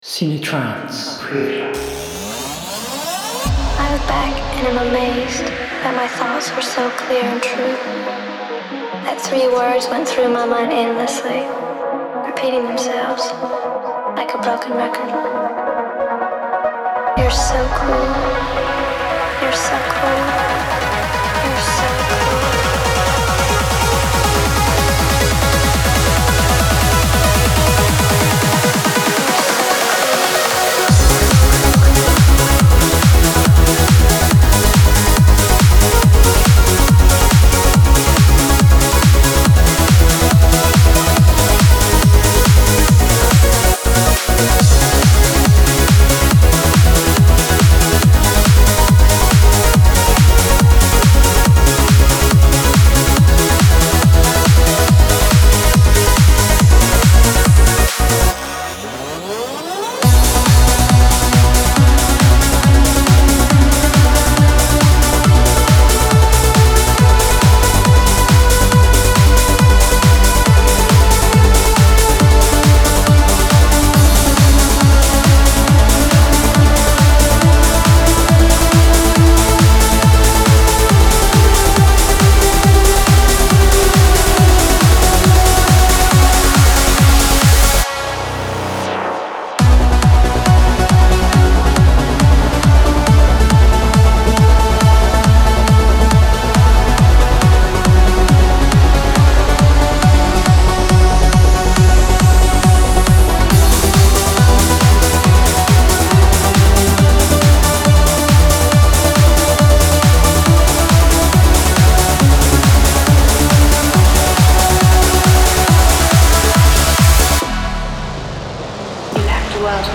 CineTrance Connection Vol.2 gives you 79 powerful trance presets for SPIRE 1.5. Get ready for an instant inspiration soundset.
Enjoy the trance Bass patches, the groovy Sequences & ARPs, the mighty Leads & Plucks, the crazy FXs and the lush Pads and Atmospheres available in CineTrance Connection Vol.2.
Genre: Trance, Psy-Trance